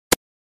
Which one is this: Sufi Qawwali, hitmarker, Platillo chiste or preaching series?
hitmarker